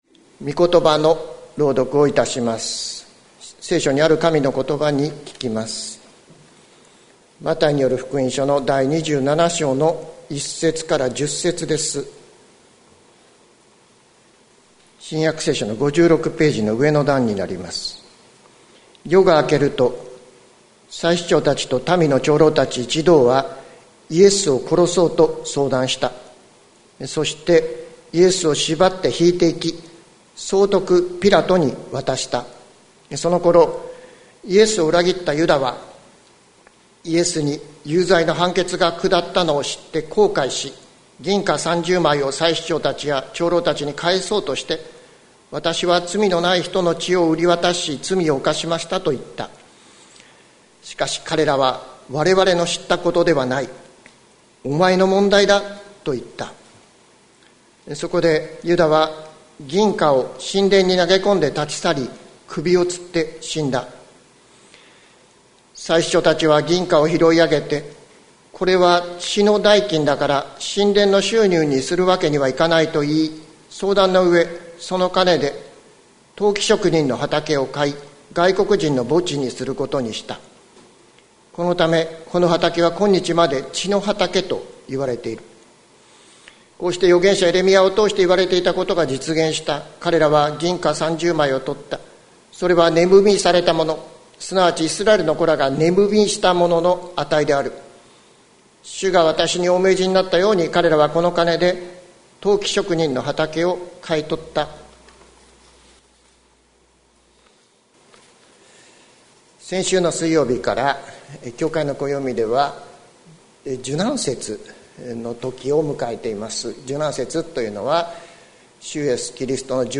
2021年02月21日朝の礼拝「死ぬな、生きろ」関キリスト教会
説教アーカイブ。